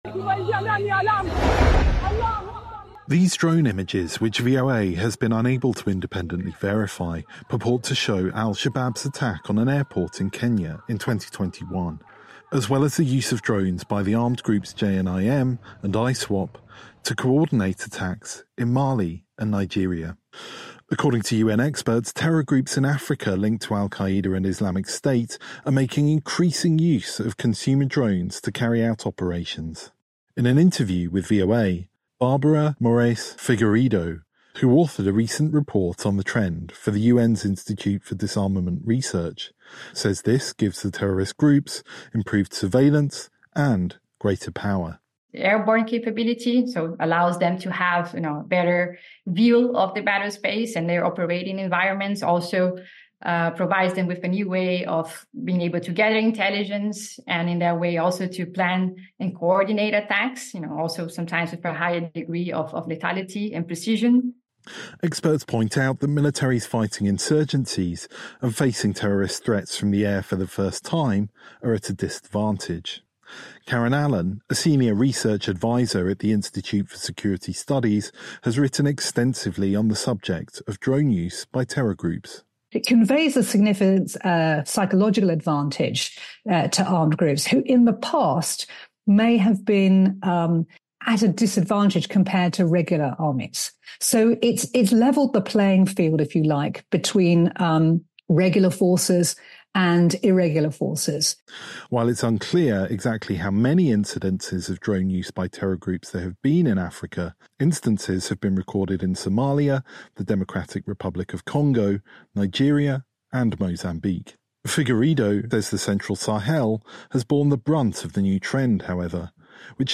In an interview with VOA, United Nations experts say terror groups in Africa are increasing their use of drones to carry out operations previously only possible to nation-states.